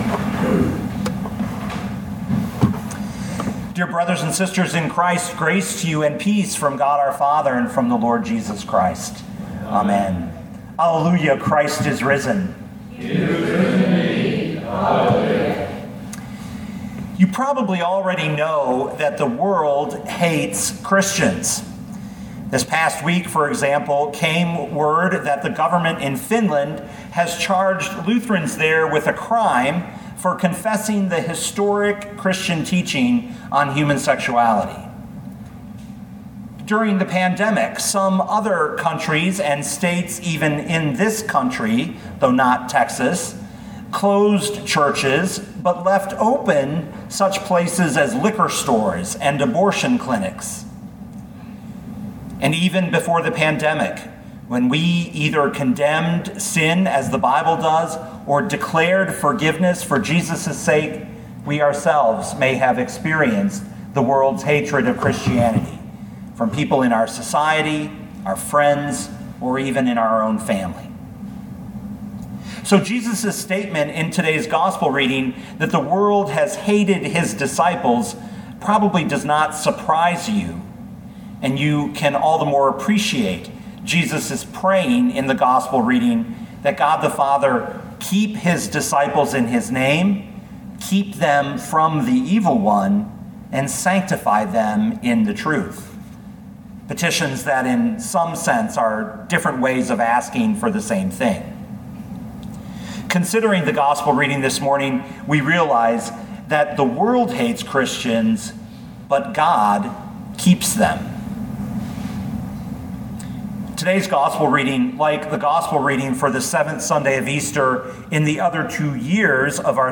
2021 John 17:11b-19 Listen to the sermon with the player below, or, download the audio.